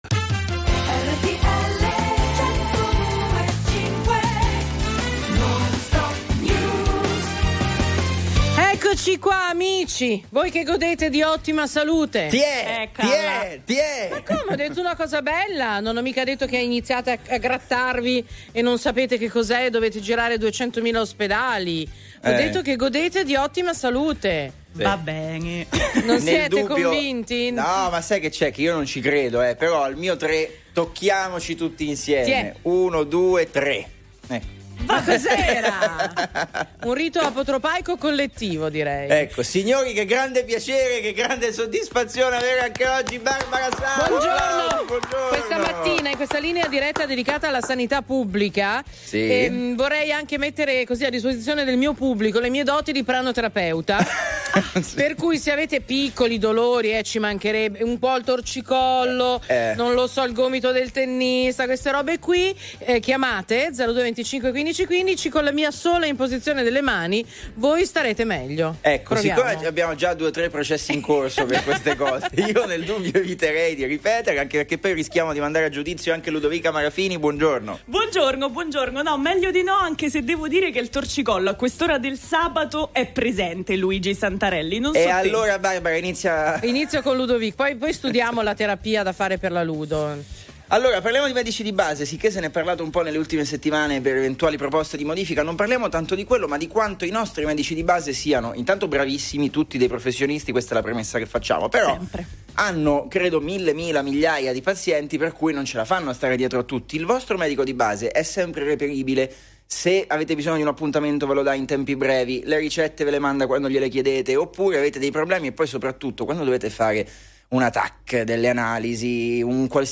… continue reading 63 tập # Notizie quotidiane # Notizie d'affari # News Talk # Notizie # Notizie sportive # Notizie di tecnologia # RTL 102.5 # Mondo dello spettacolo